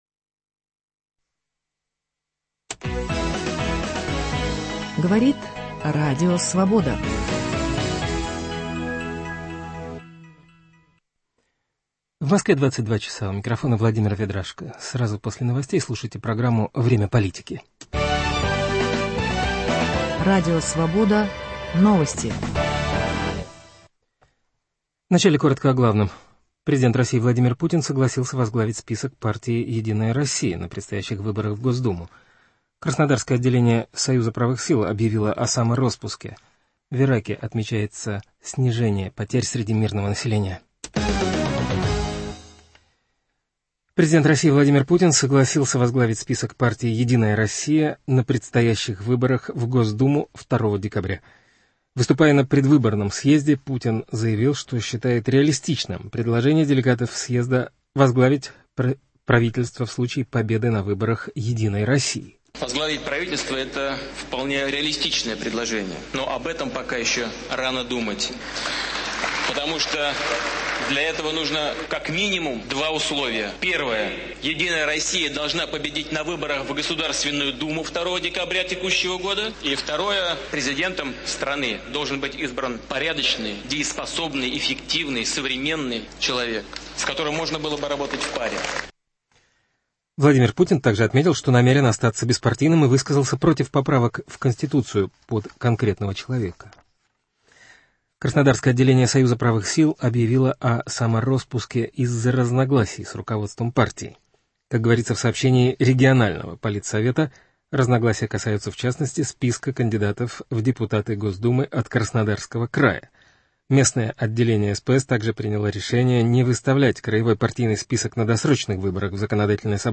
Ход избирательной кампании в России в прямом эфире обсудят политологи Сергей Марков и Дмитрий Орешкин.